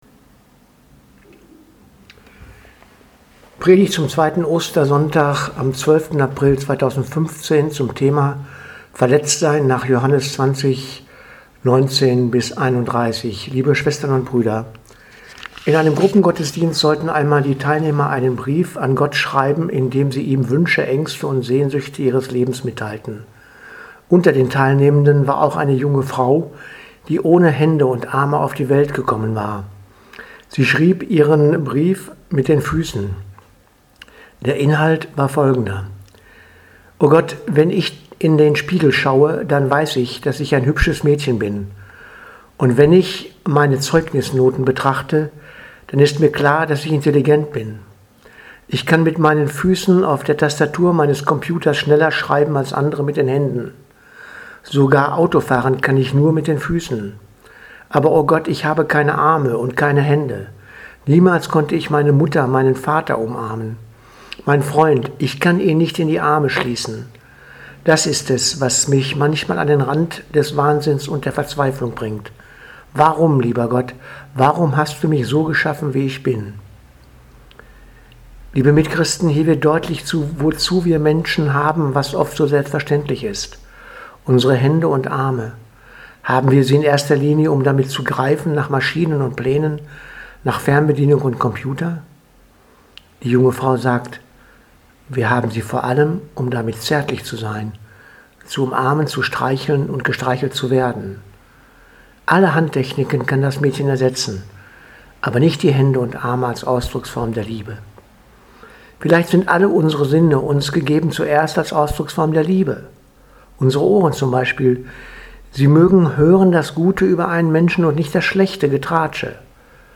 Predigt zum 2. Ostersonntag 12.04.2015